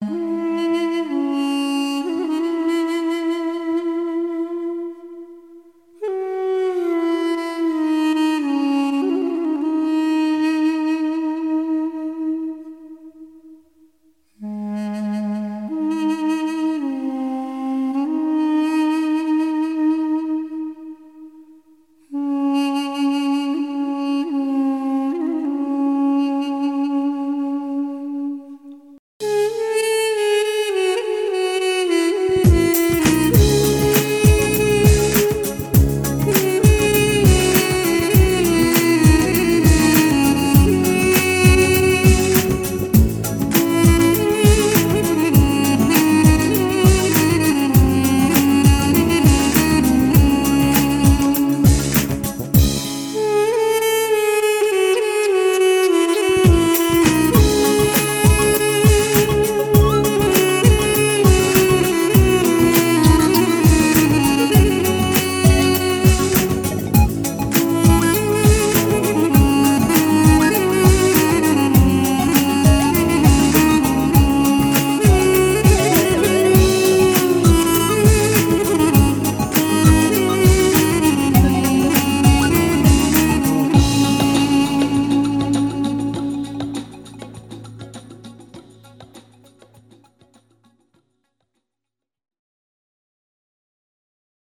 • Категория: Дудук